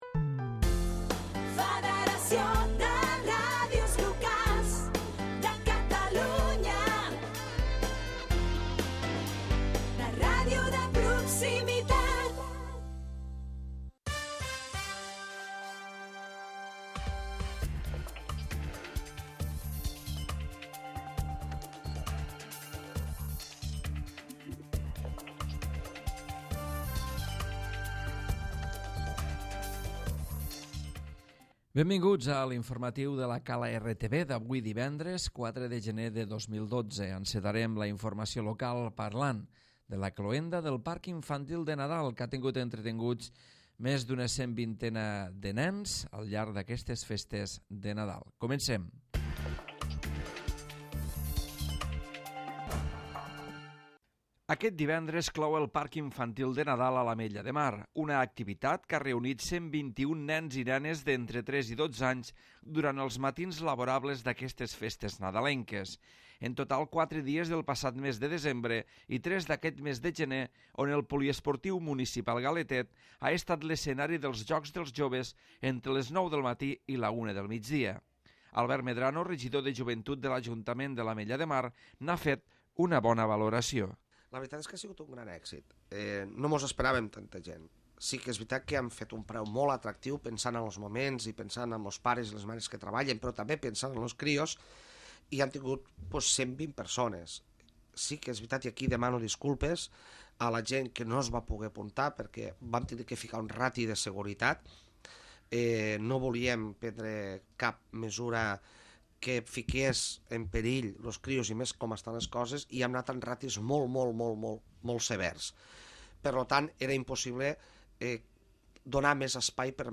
Butlletí Informatiu
Informatiu local